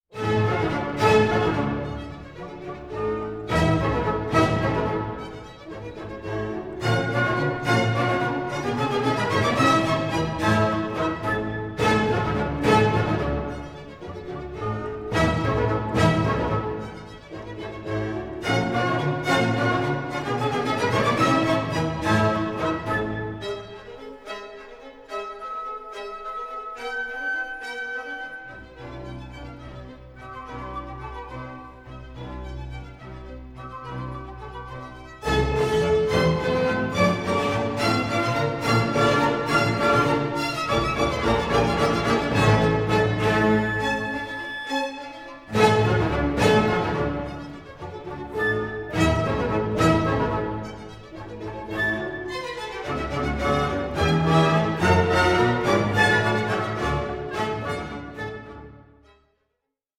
light, lyrical, and full of Italianizing elements.
period instruments, transparent, but full of fire.